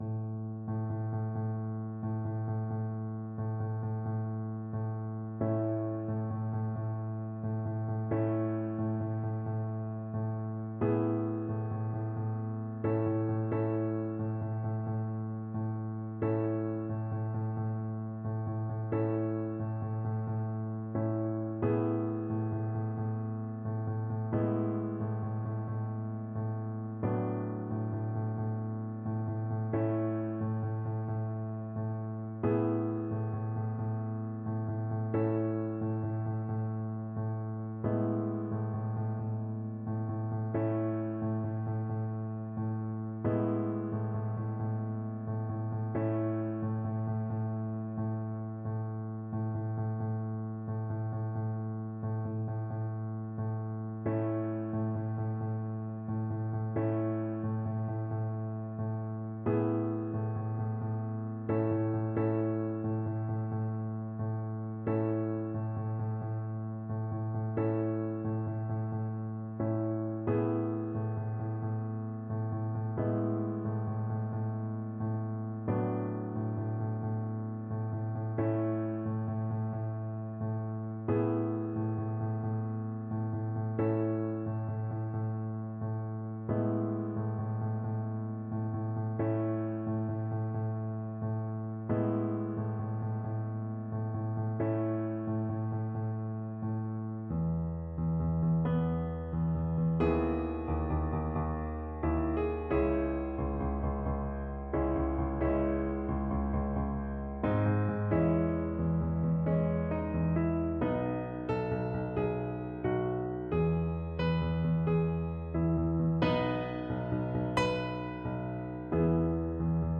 Play (or use space bar on your keyboard) Pause Music Playalong - Piano Accompaniment Playalong Band Accompaniment not yet available transpose reset tempo print settings full screen
Nobilmente = c. 60
A major (Sounding Pitch) (View more A major Music for Violin )
Traditional (View more Traditional Violin Music)